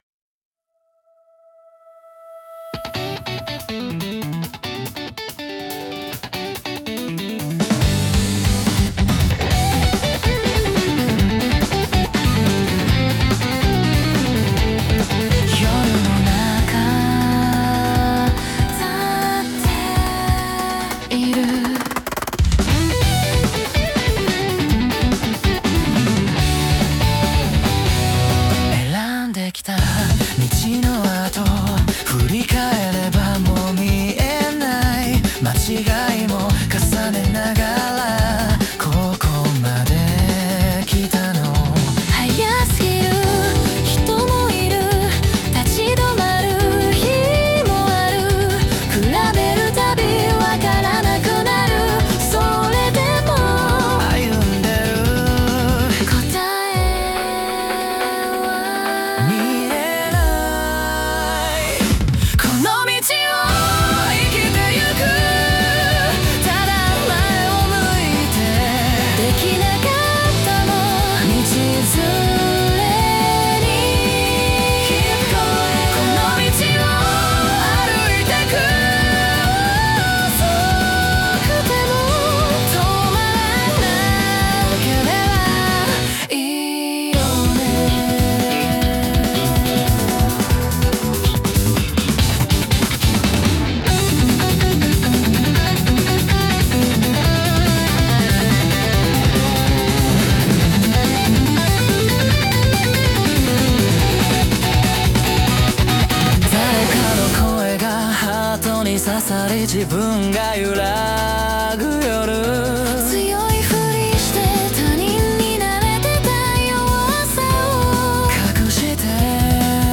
デュエット
イメージ：J-ROCK,デュエット,かっこいい,切ない